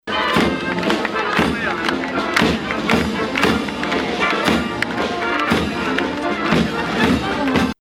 Evenimentele de azi s-au încheiat cu o emoţionantă demonstraţie a fanfarei militare
muzica.mp3